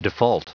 Prononciation du mot default en anglais (fichier audio)
Prononciation du mot : default